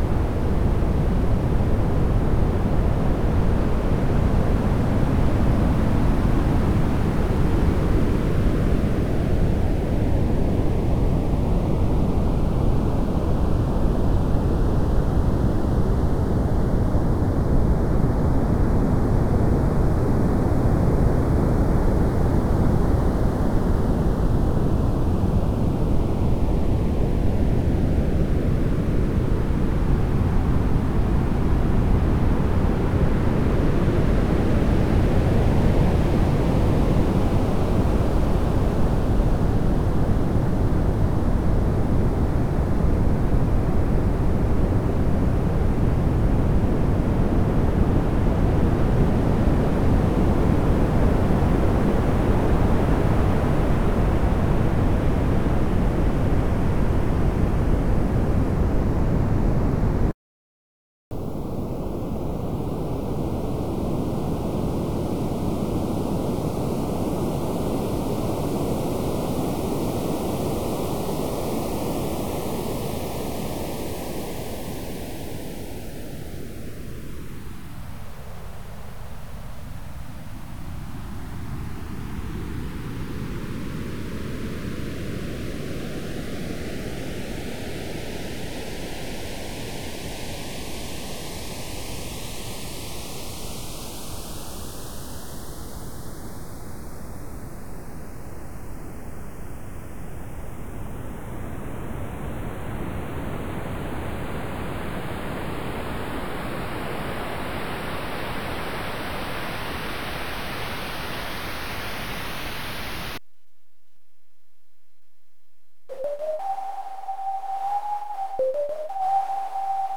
Background filtered noise generator aka Noise constructor
arbitrary spectrum colored background filtered noise generator
noiseex.ogg